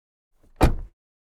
car_door_close_001.wav